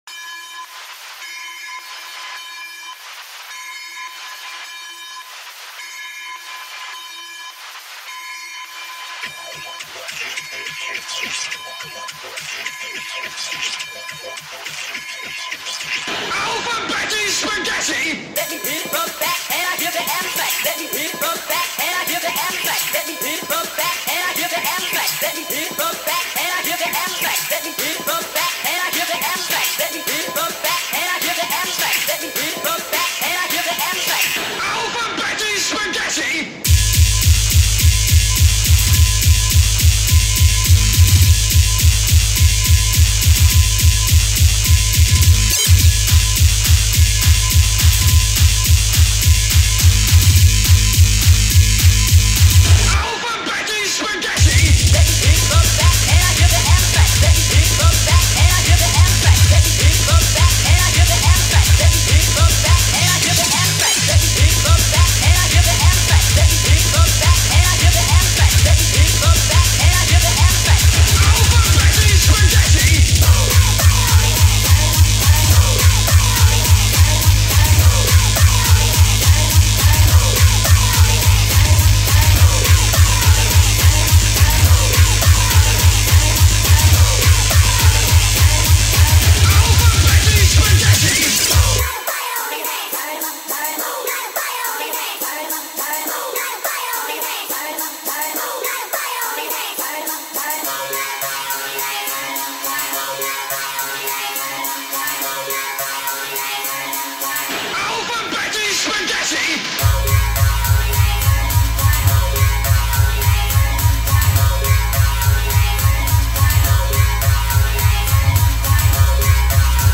BPM: 210 Genre: Frenchcore/Terror